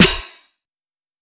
Snare (1).wav